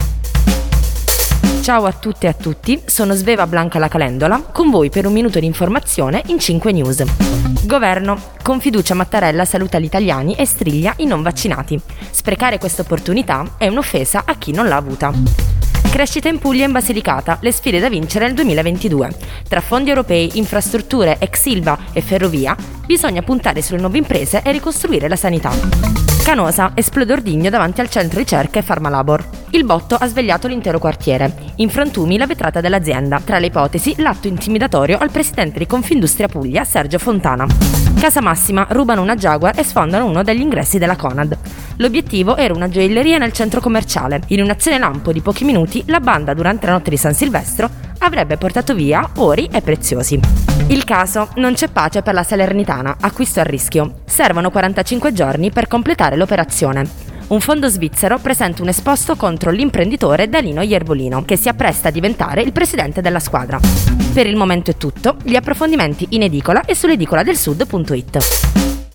Giornale radio alle ore 19.